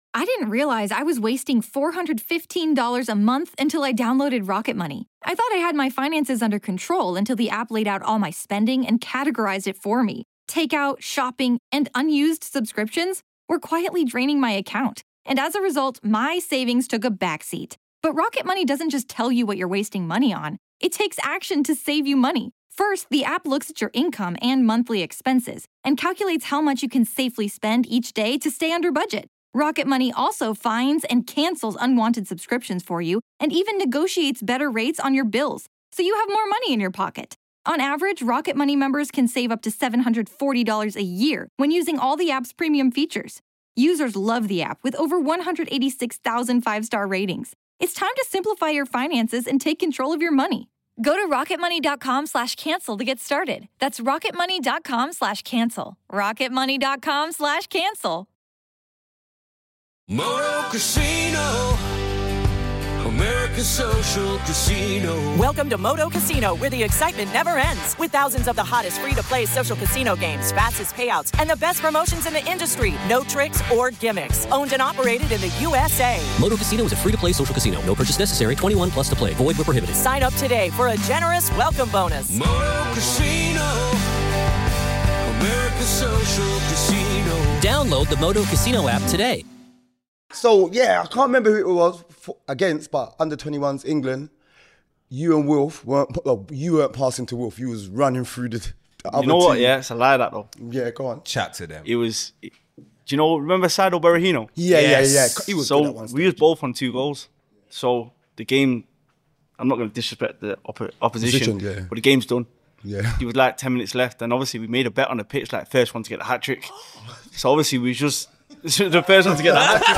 RAVEL MORRISON JOINS FILTHY FELLAS FOR AN EXCLUSIVE INTERVIEW! | FILTHY @ FIVE